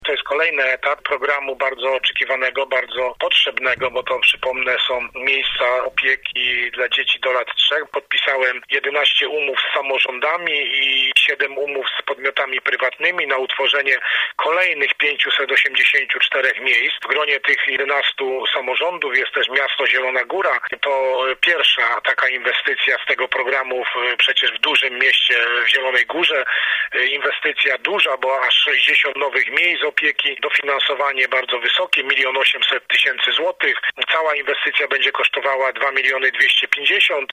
Wojewoda lubuski Władysław Dajczak podkreśla, że miasto Zielona Góra jest jednym z największych beneficjentów programu Maluch+: